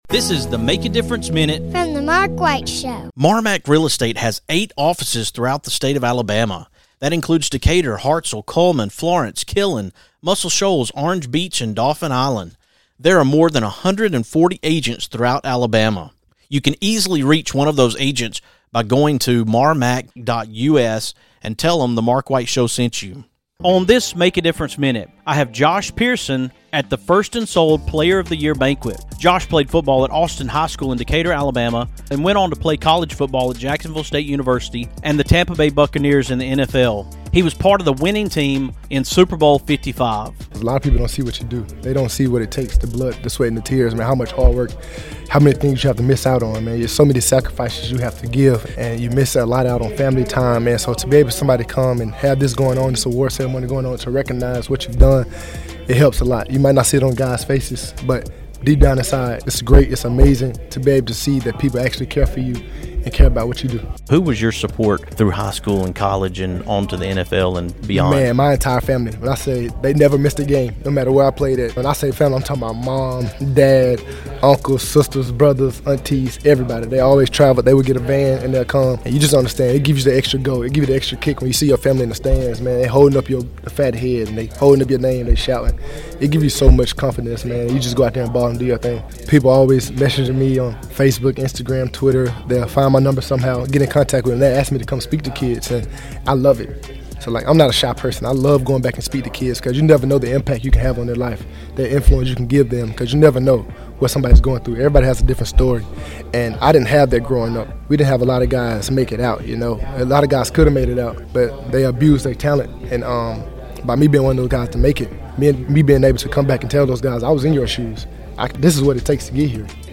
On today’s MADM, I’m coming to you from 1st & Sold-MarMac Real Estate Football in the South Player of the Year Banquet!